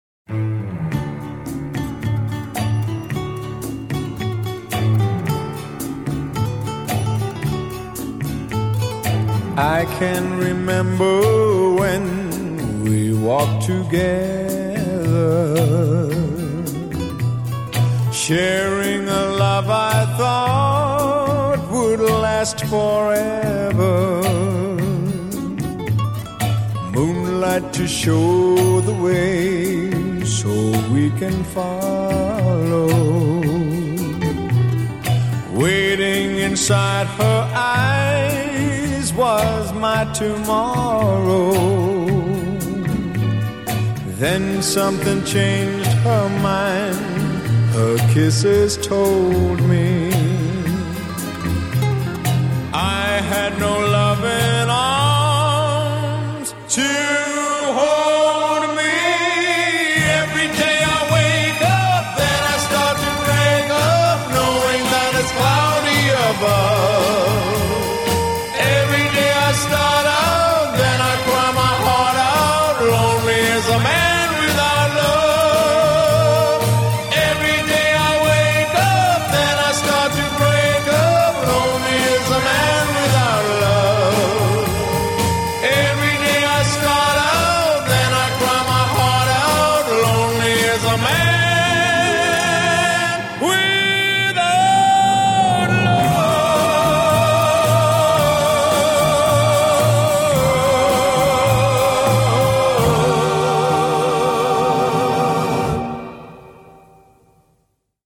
BPM37-115
MP3 QualityMusic Cut